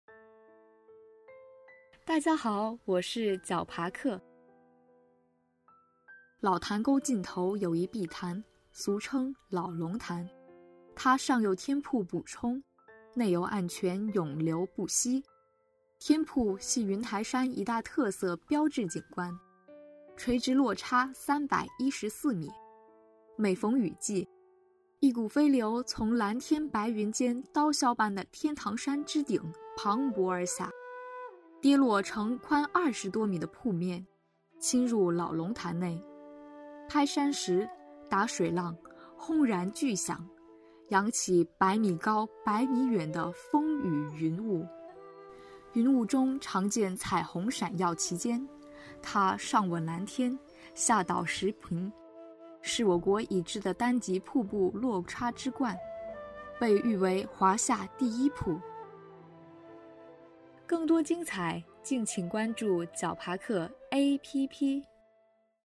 老龙潭与天瀑----- 手机用户 解说词: 老潭沟尽头有一碧潭，俗称老龙潭。